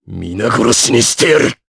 Riheet-Vox_Skill5_jp.wav